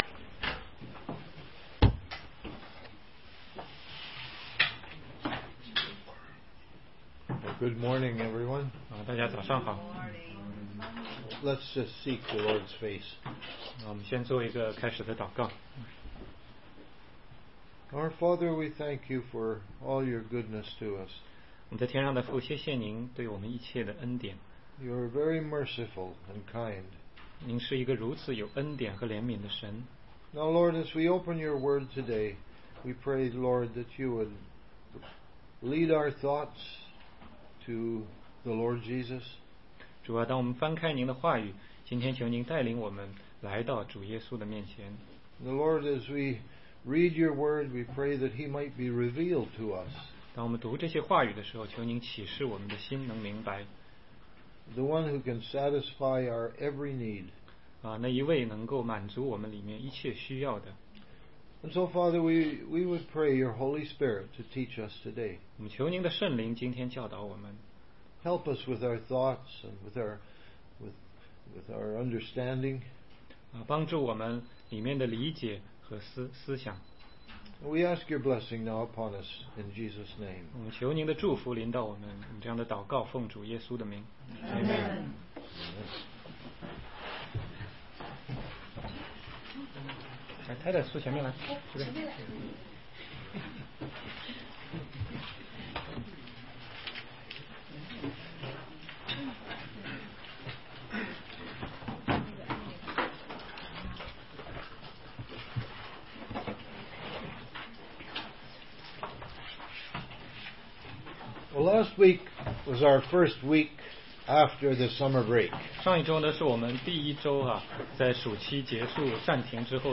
16街讲道录音 - 约翰福音概览续